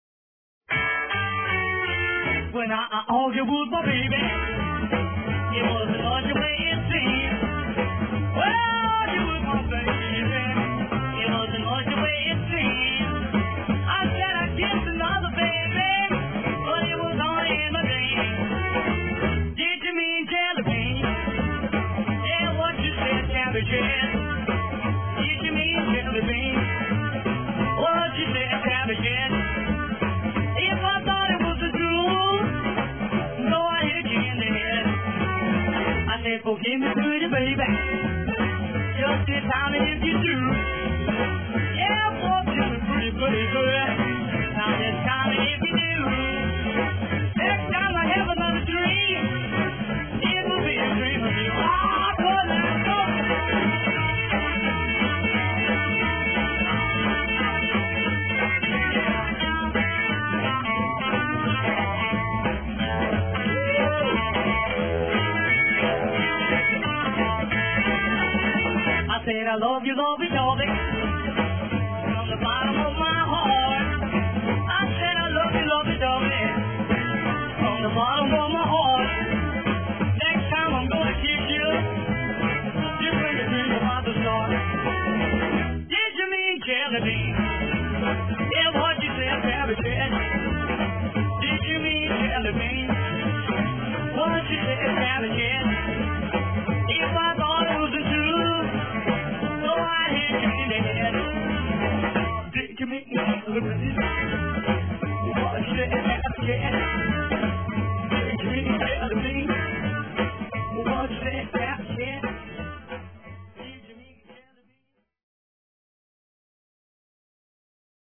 wild rockabilly sounds